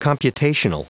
Prononciation audio / Fichier audio de COMPUTATIONAL en anglais
Prononciation du mot computational en anglais (fichier audio)